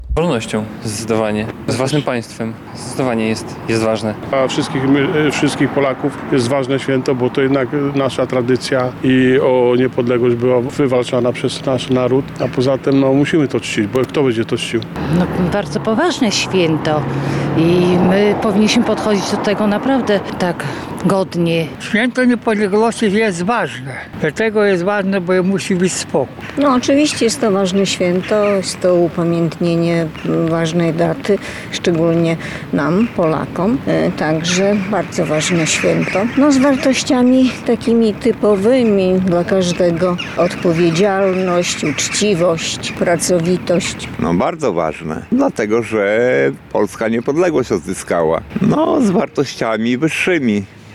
Na ulicach Suwałk o Święcie Niepodległości
Postanowiliśmy zapytać przechodniów na ulicach Suwałk, z jakimi wartościami kojarzy im się to święto.